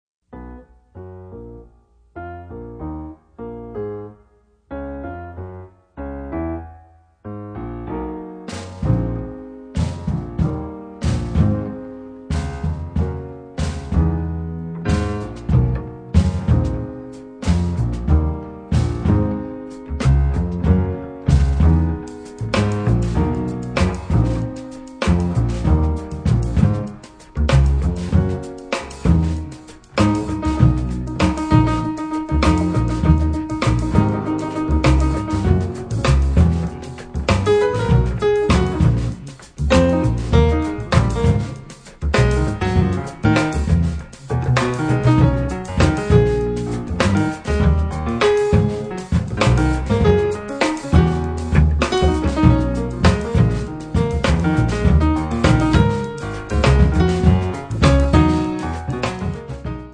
pianoforte
basso
batteria